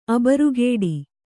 ♪ abarugēdi